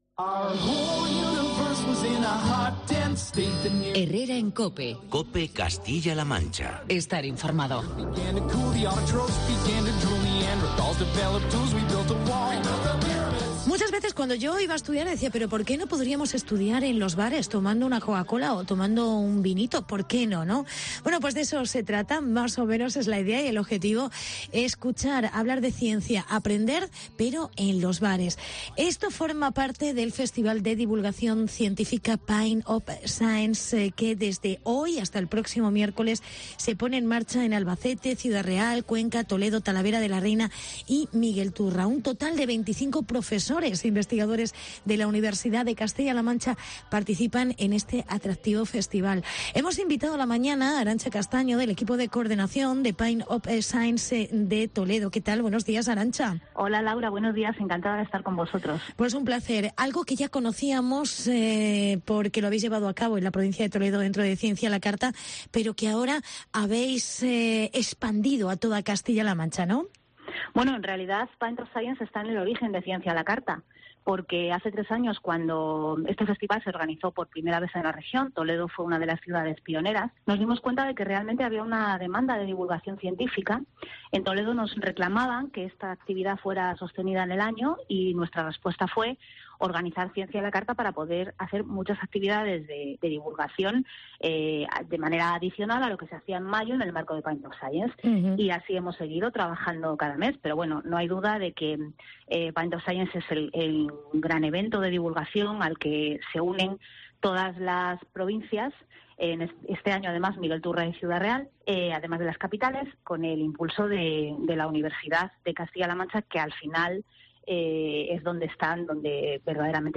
"Pint of Science". Ciencia en los bares de CLM . Entrevista